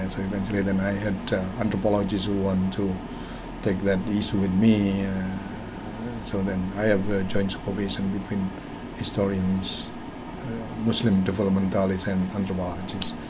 S1 = Indonesian male S2 = Malaysian female Context: S1 is talking about his experience when doing his original research in Islamic history.
All of this is spoken rather quietly and quite fast.